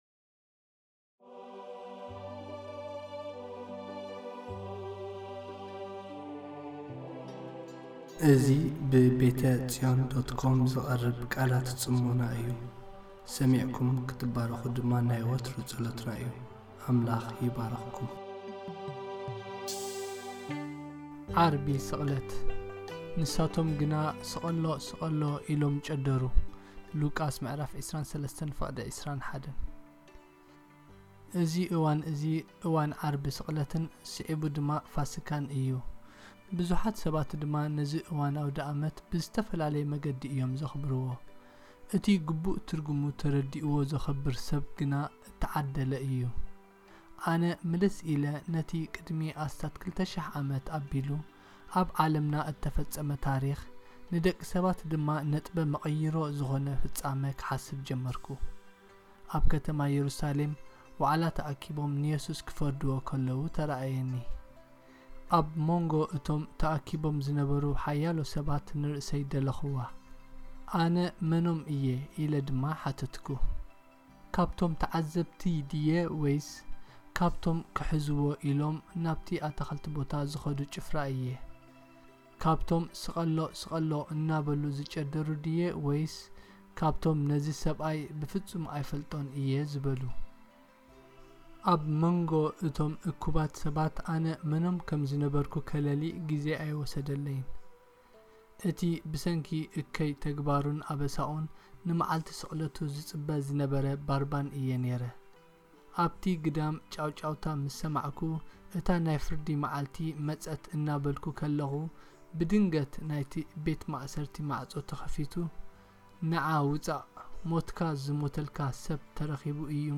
Devotional posted by